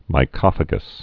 (mī-kŏfə-gəs)